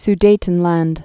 (s-dātn-lănd, -länt, z-)